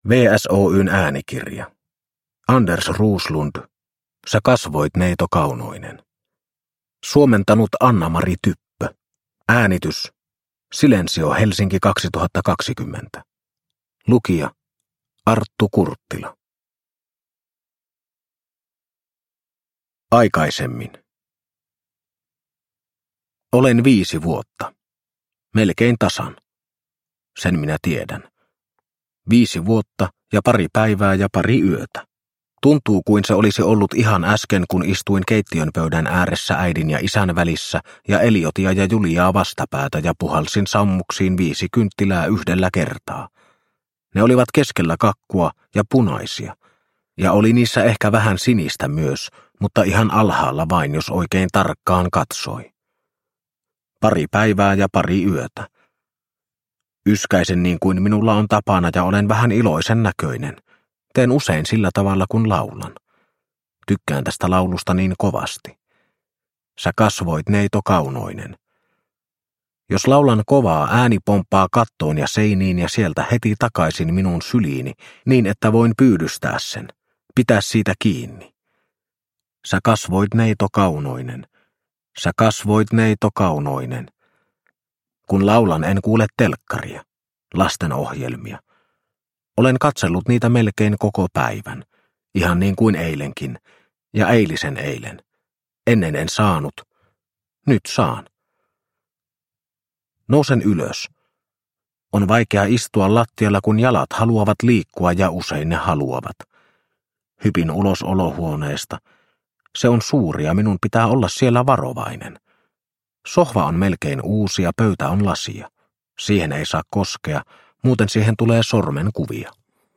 Sä kasvoit neito kaunoinen – Ljudbok – Laddas ner